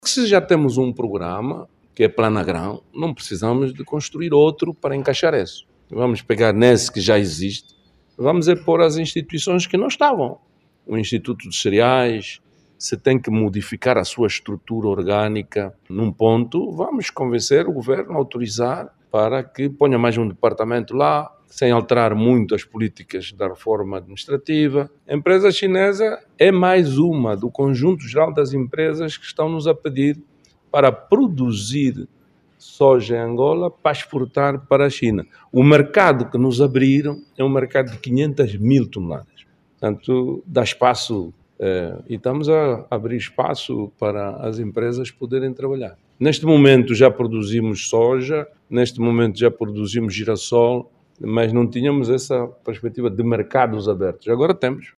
O Ministro da Agricultura e Florestas, Isaac dos Anjos, esclareceu que esta  é uma iniciativa interessante, que pode ser incluída  no Planagrão.
ISAAC-DOS-ANJOS-07HRS.mp3